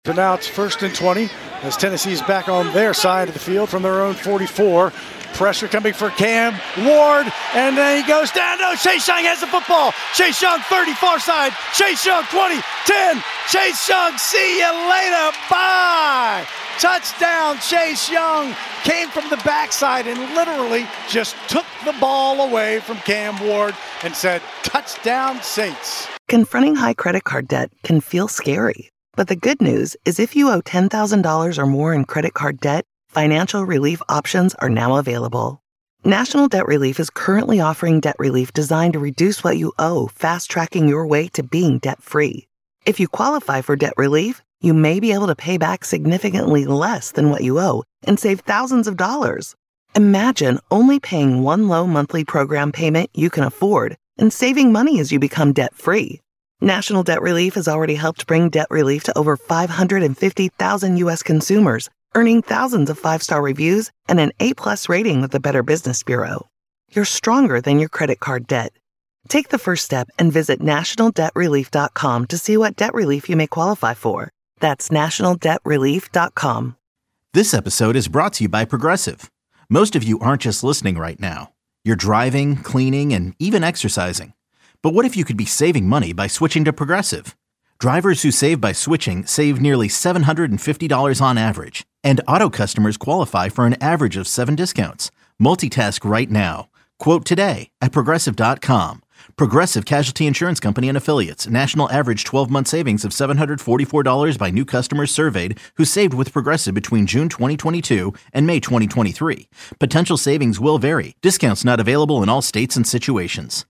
Saints interviews, press conferences and more